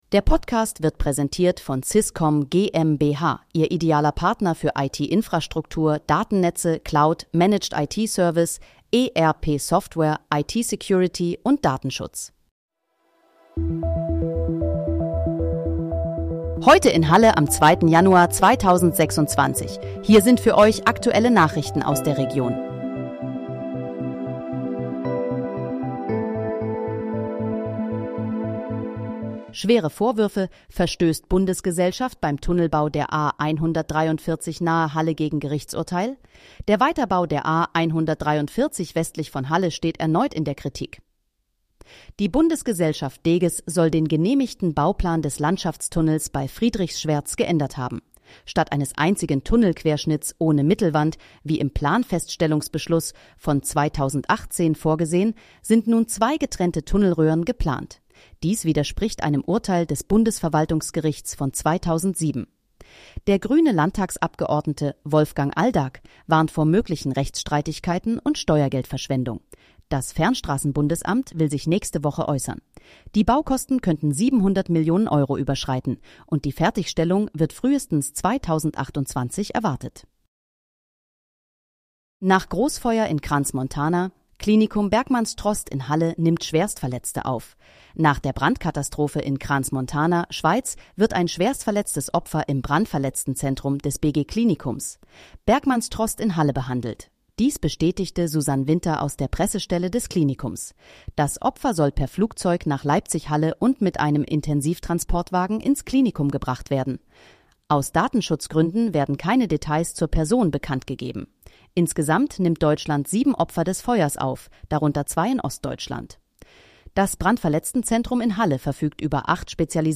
Heute in, Halle: Aktuelle Nachrichten vom 02.01.2026, erstellt mit KI-Unterstützung
Nachrichten